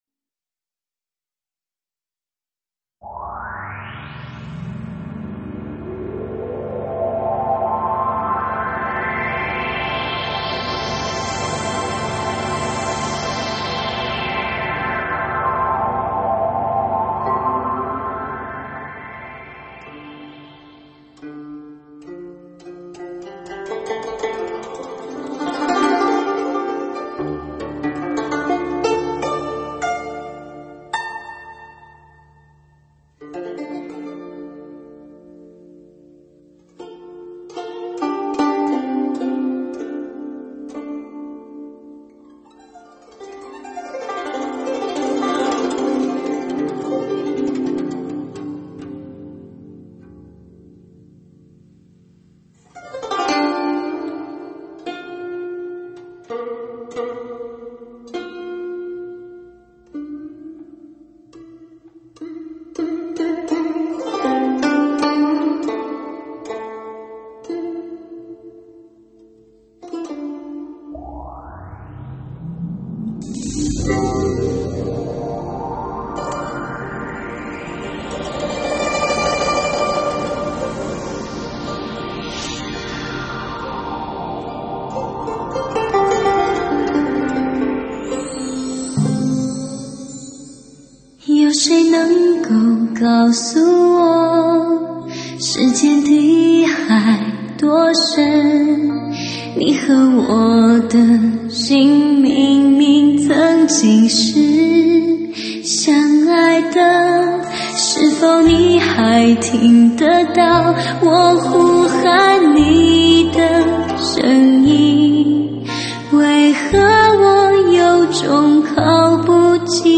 上甜而不腻之嗓音绝对讨好你挑剔睥双耳。
，很甜的女声！